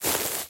脚步声 " 脚步声草 1
描述：在草样品的唯一脚步。